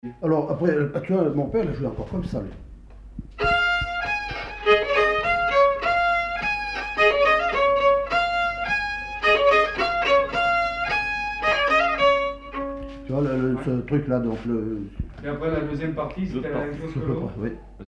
Ugine
danse : polka piquée
circonstance : bal, dancerie
Pièce musicale inédite